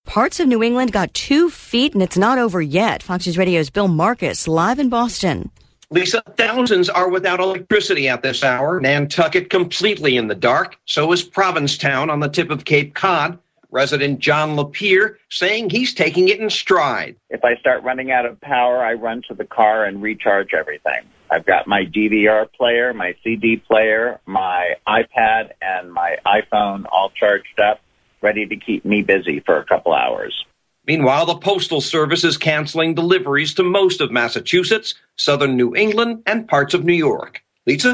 12 NOON LIVE